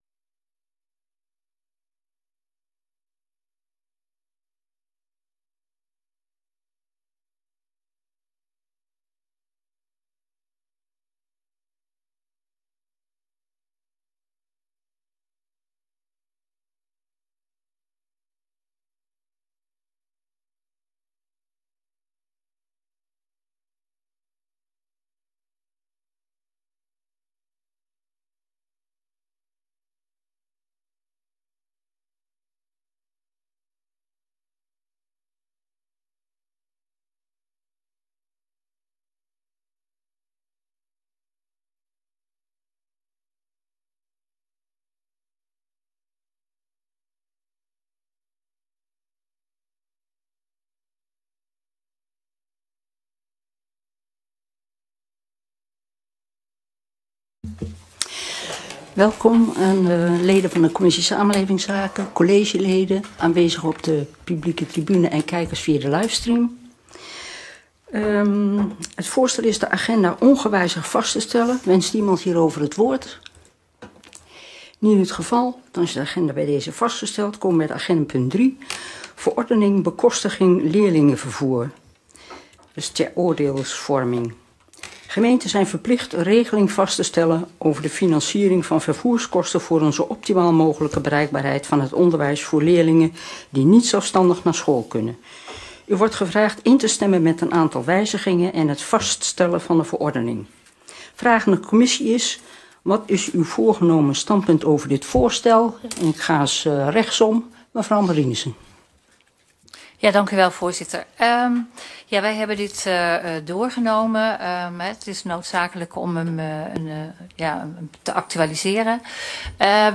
Vergadering van de commissie Samenlevingszaken op maandag 22 mei 2023, om 19.30 uur eerst samen met de commissie Woonomgeving in de raadzaal, daarna vanf 20.15 uur fysiek in kamer 63 van het gemeentehuis.